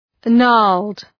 Shkrimi fonetik {nɑ:rld}
gnarled.mp3